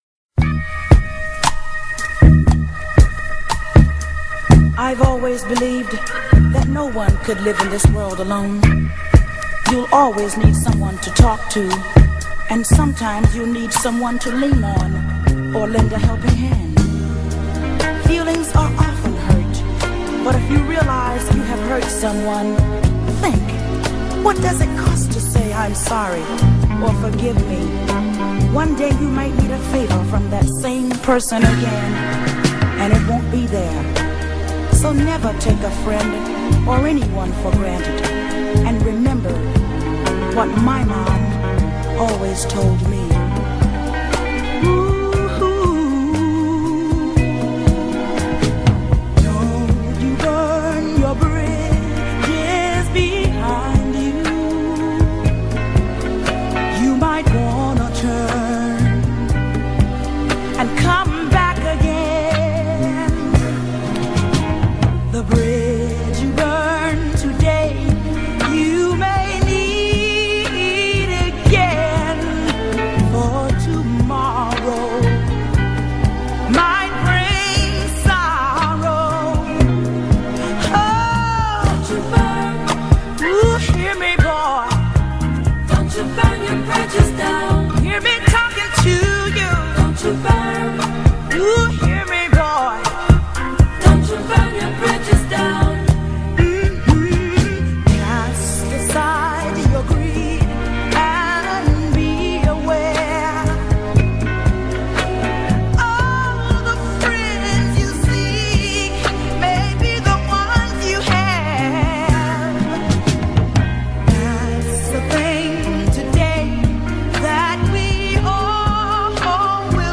You can here our chat here: